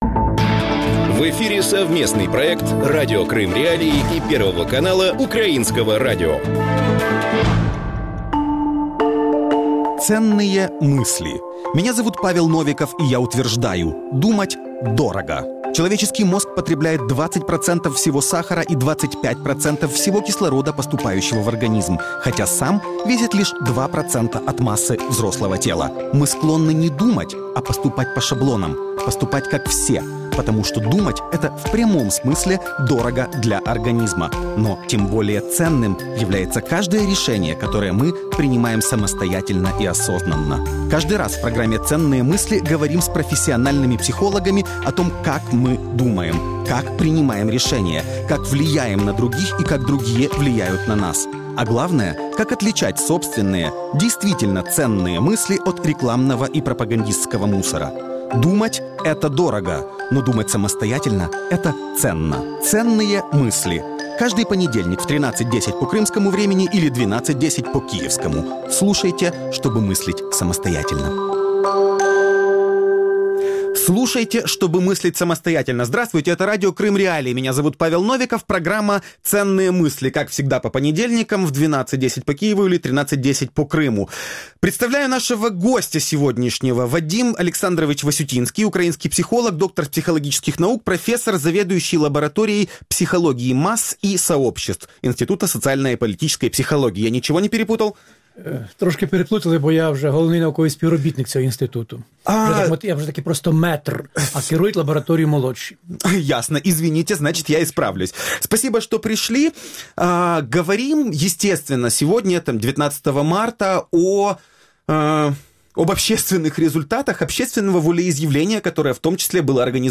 Гость студии – украинский психолог, доктор психологических наук, профессор